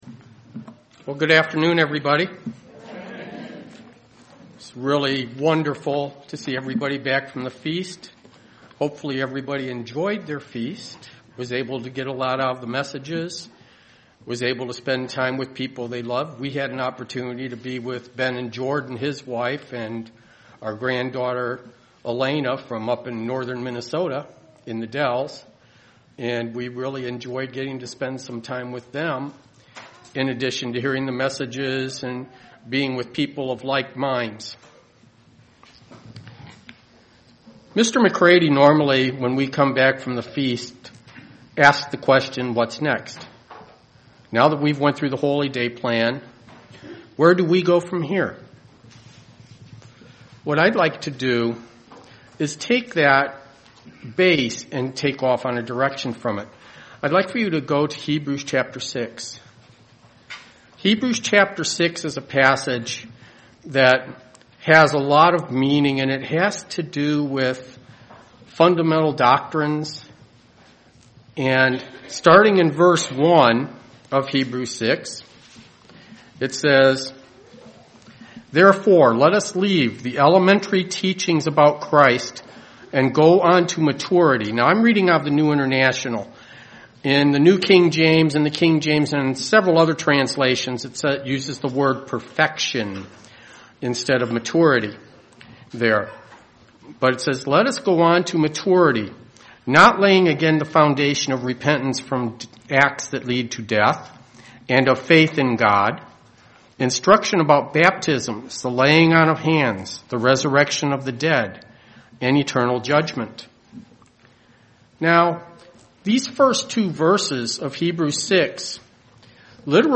Print Explore four aspects of Maturity: Mental, Physical, Emotional, and Spiritual UCG Sermon Studying the bible?